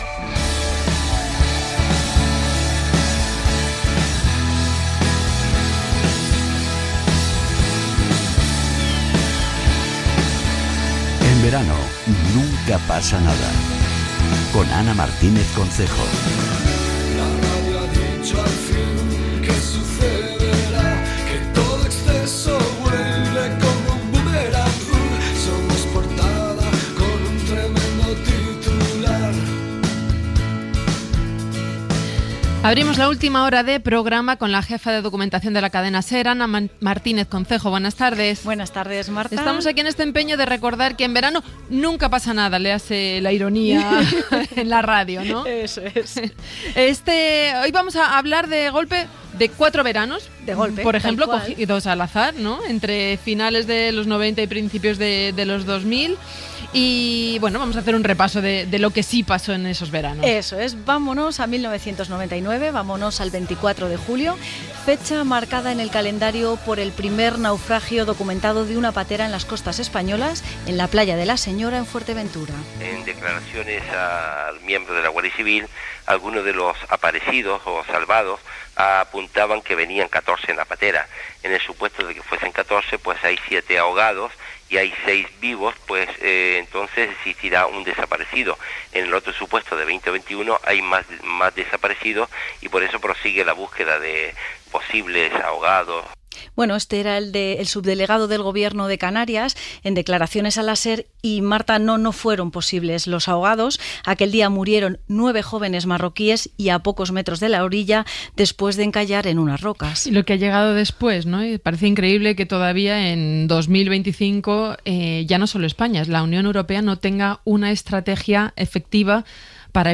Secció "En verano nunca pasa nada" amb enregistraments de notícies emeses per la Cadena SER en estius dels anys passats
Entreteniment